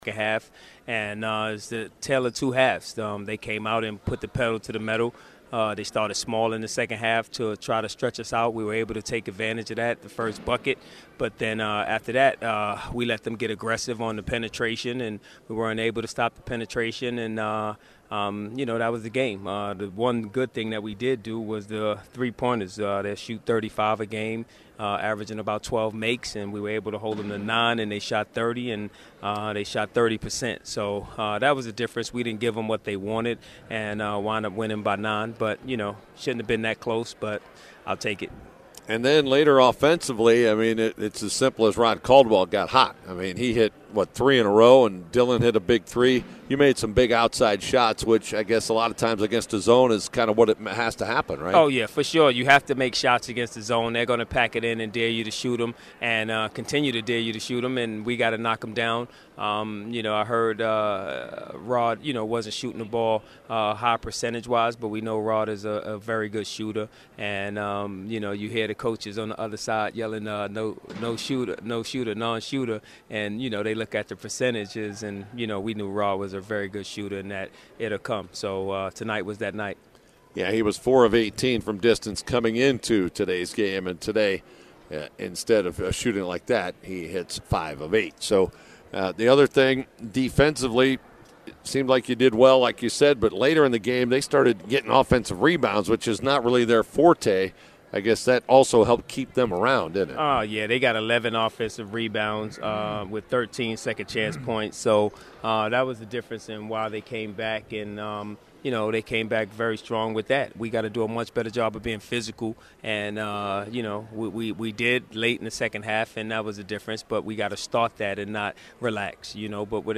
Post-Game Audio:
USCU 8 Coaches Postgame comments.mp3